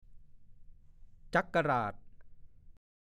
ฐานข้อมูลพจนานุกรมภาษาโคราช
1. ชื่ออำเภอหนึ่งในจังหวัดนครราชสีมา มีลำน้ำจักราชไหลผ่าน คนโคราชออกเสียงเป็น จั๊ก-กะ-หราด